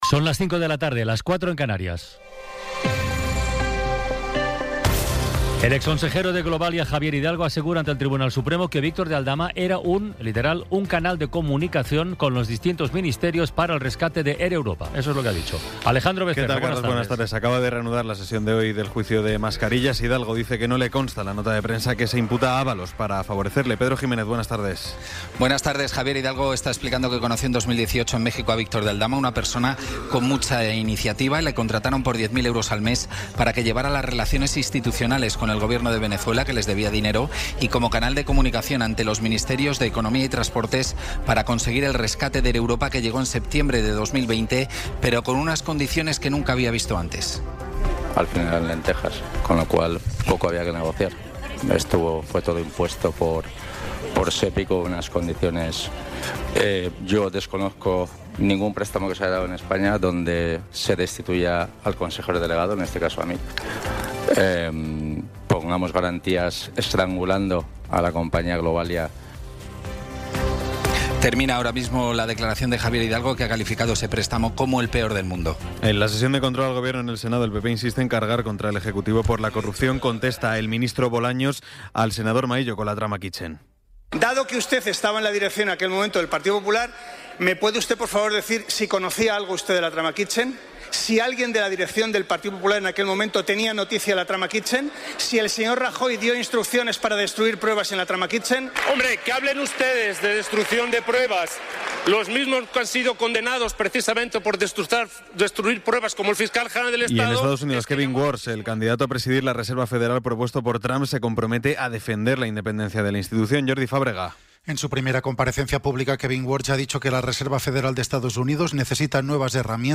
Resumen informativo con las noticias más destacadas del 21 de abril de 2026 a las cinco de la tarde.